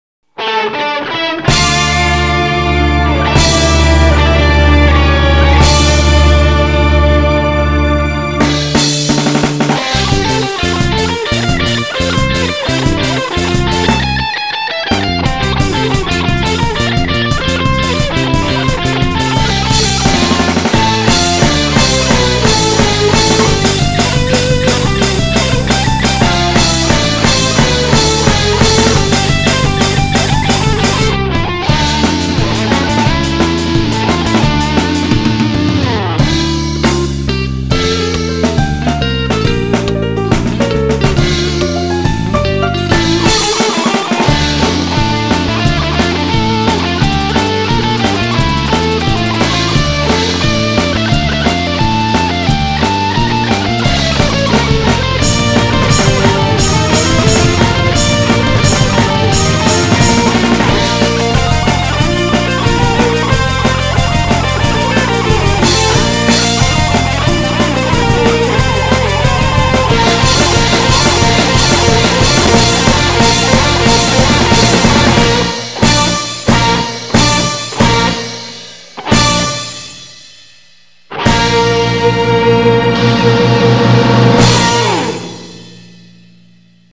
guitar instrumental
このページの曲はすべてコピー（またはｶｳﾞｧｰ）です。